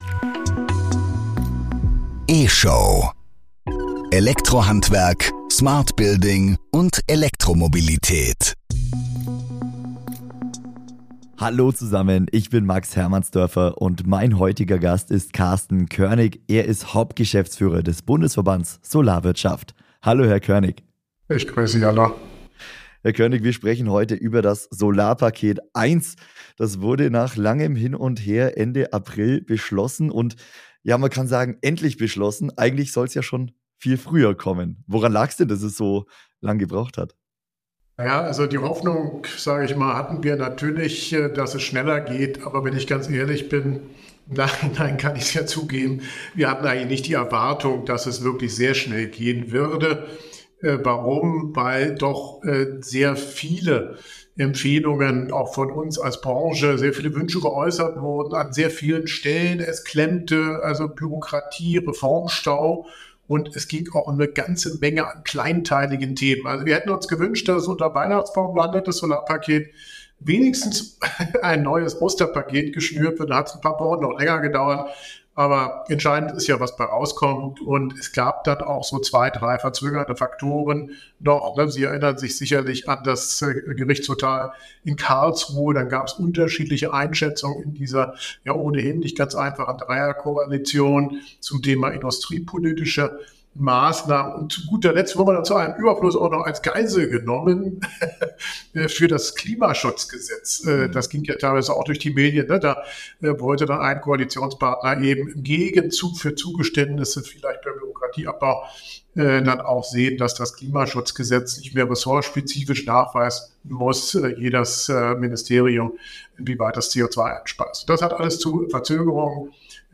Heute im Interview